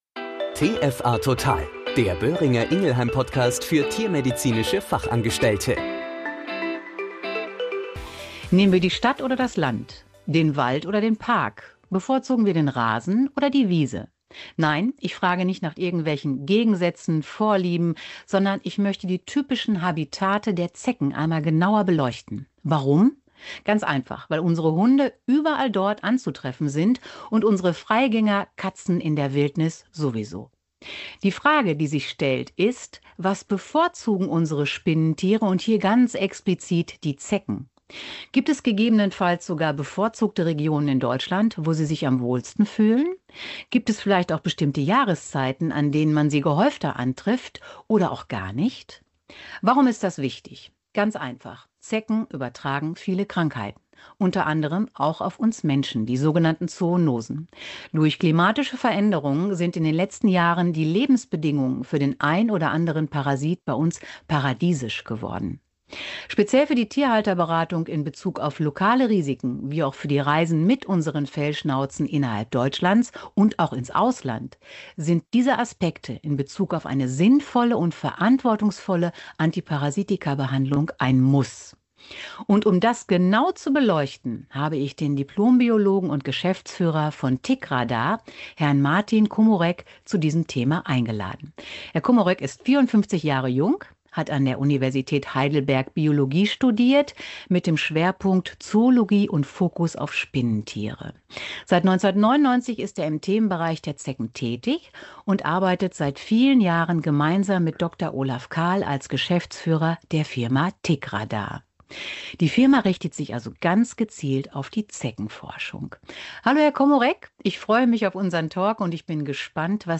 Wie sich parallel dazu die Situation der Zeckenpopulation in Deutschland entwickelt und entwickelt hat, wird in diesem Interview aufgezeigt. Welche Arten sind immer noch stark vertreten, welche sind eventuell im Anmarsch und welche Konsequenzen lassen sich zum Schutz für Mensch und Tier daraus schließen?